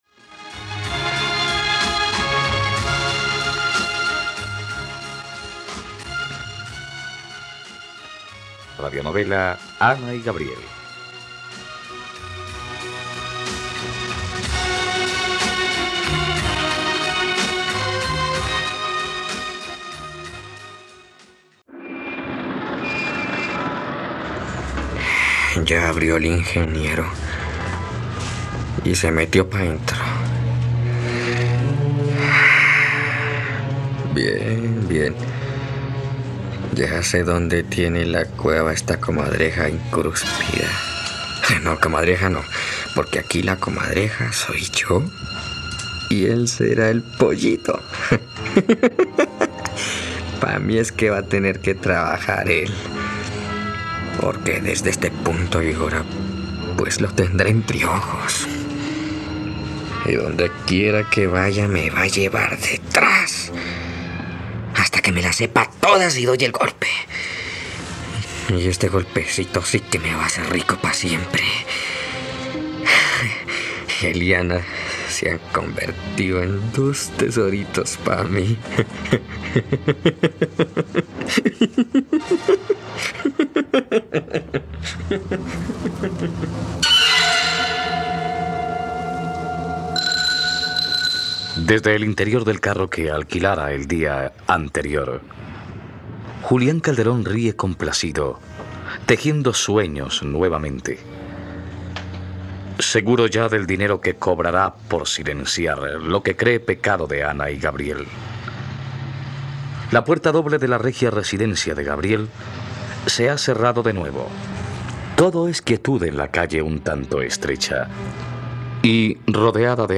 ..Radionovela. Escucha ahora el capítulo 57 de la historia de amor de Ana y Gabriel en la plataforma de streaming de los colombianos: RTVCPlay.